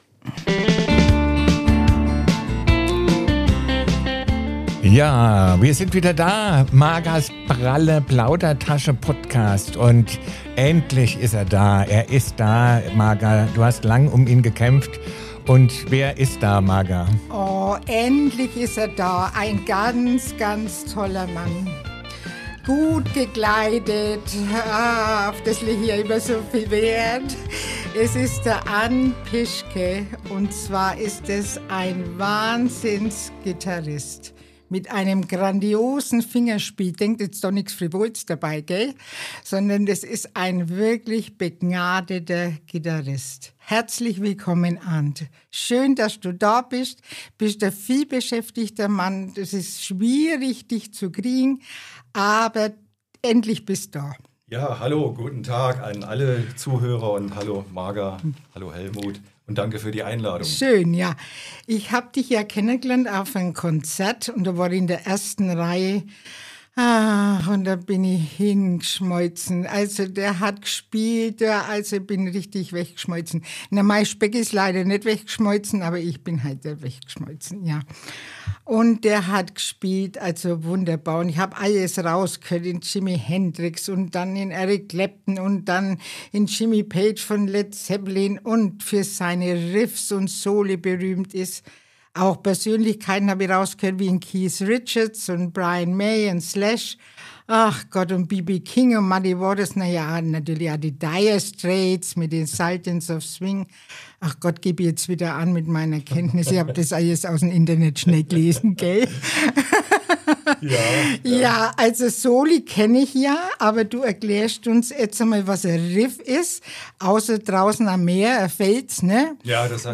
Hört rein, lasst euch inspirieren und genießt eine Folge voller Musik, Humor und echter Leidenschaft.